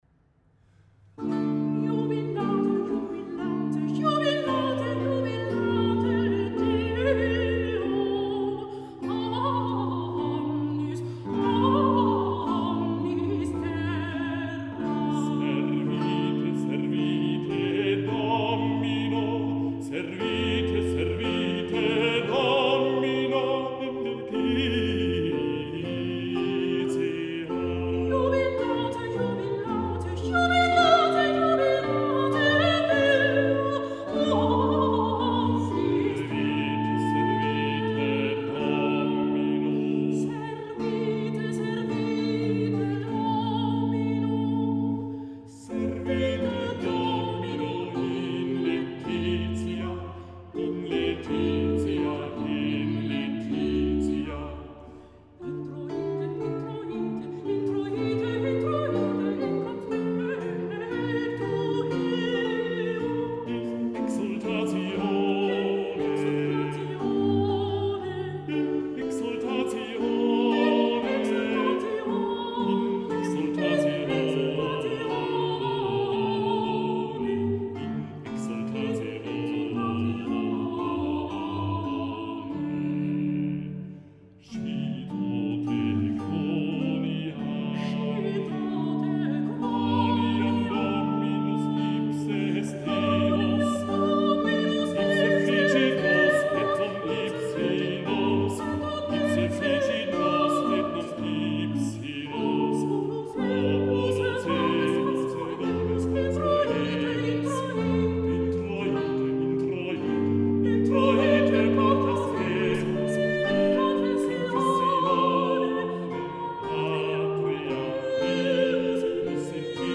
Vêpres vénitiennes pour la naissance de Louis XIV
Cantus Cölnn                    (Harmonia Mundi)